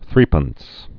(thrĕpəns, thrĭp-, thrŭp-)